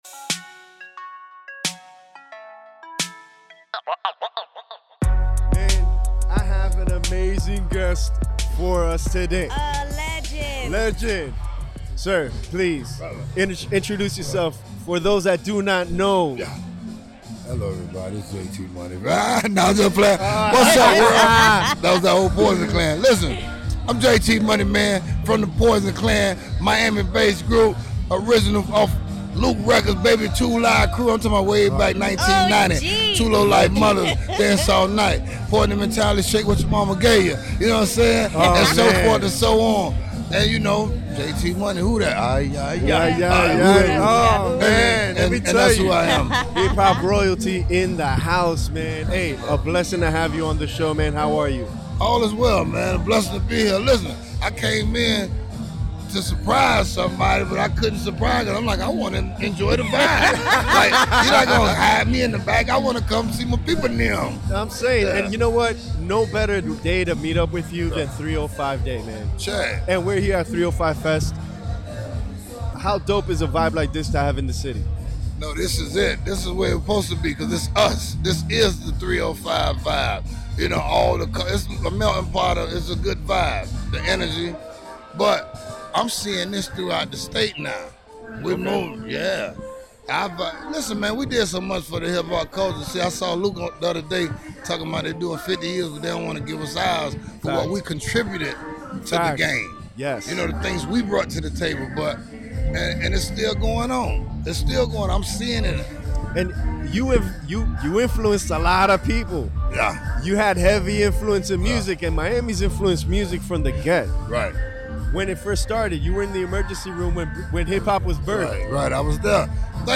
We were recording live at 305 Fest at Oasis in Wynwood.